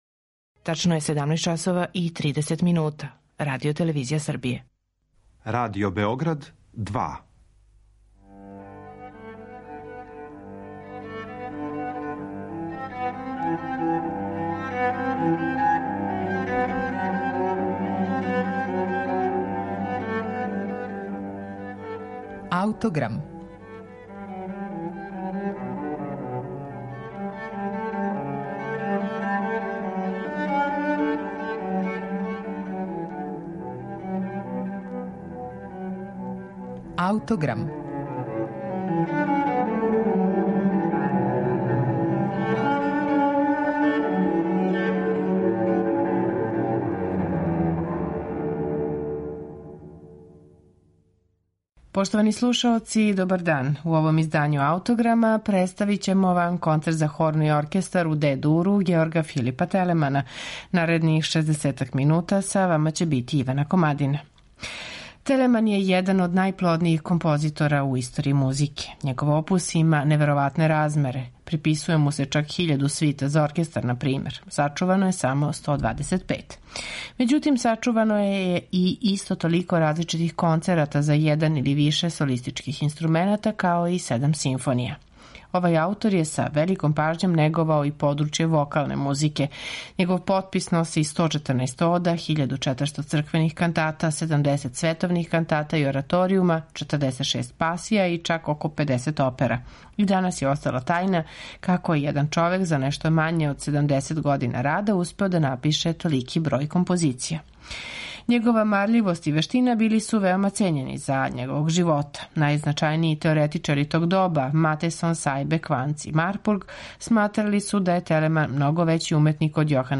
Г. Ф. Телеман: Концерт за хорну
камерног оркестра